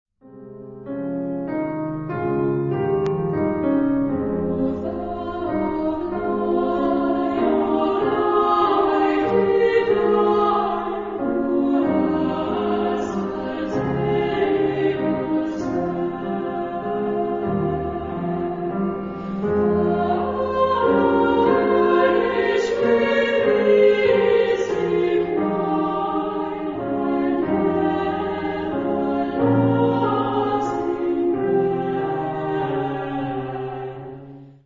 Genre-Style-Forme : Motet ; Sacré ; Hymne (sacré)
Instrumentation : Clavier  (1 partie(s) instrumentale(s))
Tonalité : fa mineur